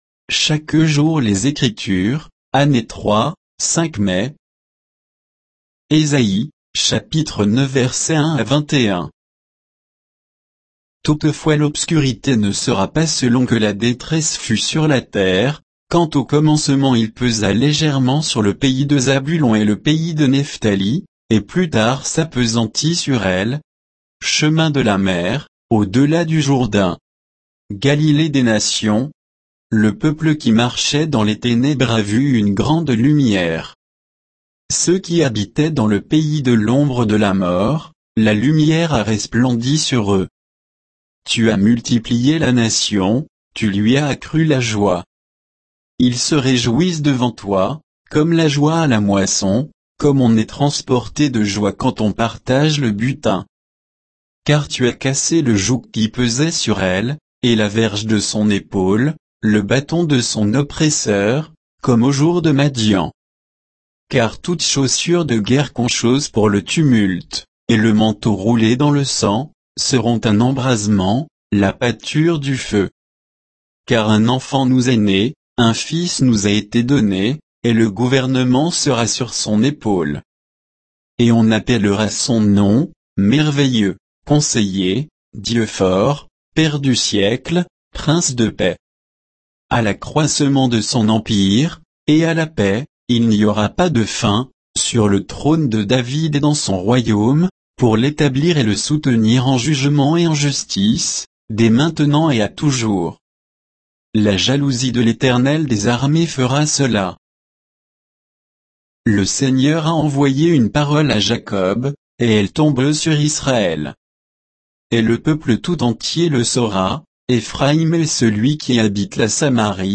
Méditation quoditienne de Chaque jour les Écritures sur Ésaïe 9, 1 à 21